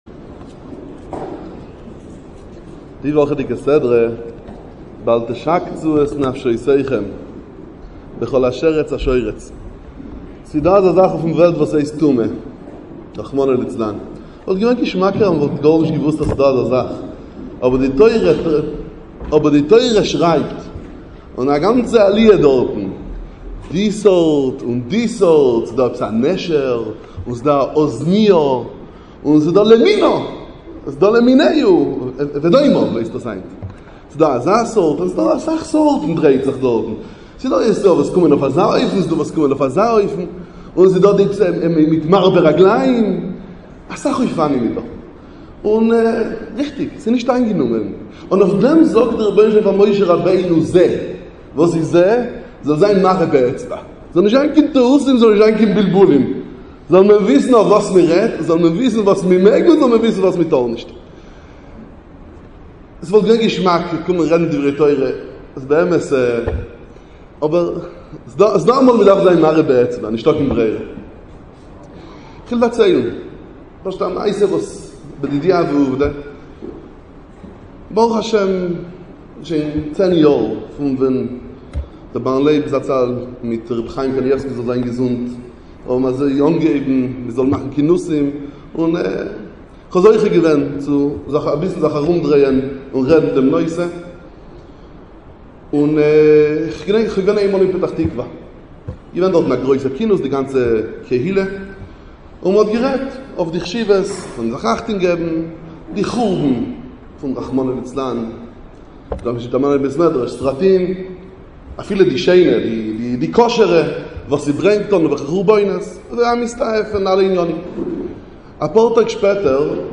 דרשת חיזוק והתעוררות לשמירת הבית מהפגעים המתחדשים